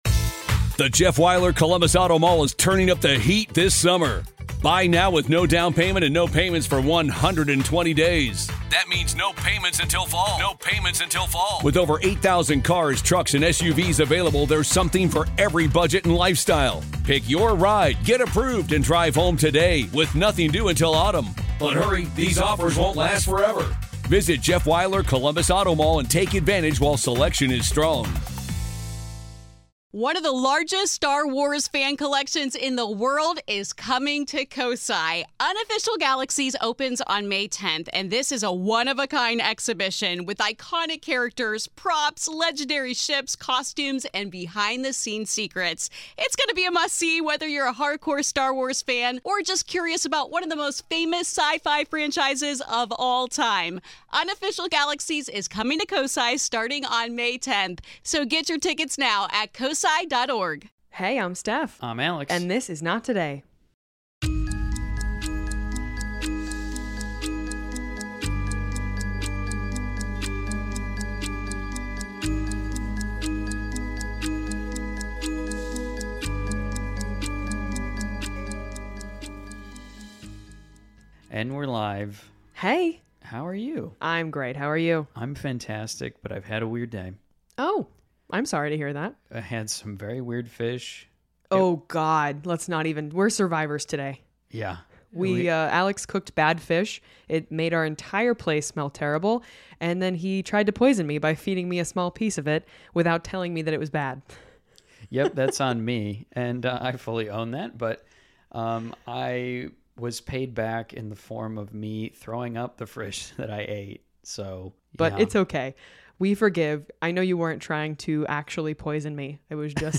In this episode we'll be reading an epic tale submitted by our listeners who almost lost their lives while cave diving in Florida!